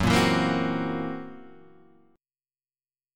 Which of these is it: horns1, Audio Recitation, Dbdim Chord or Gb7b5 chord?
Gb7b5 chord